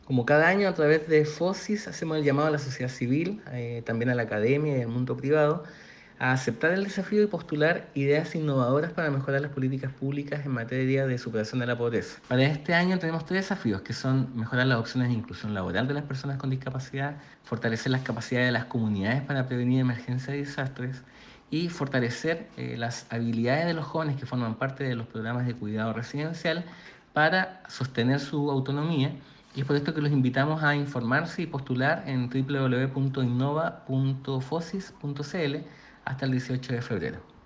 cuna-seremi-mideso-innovafosis.mp3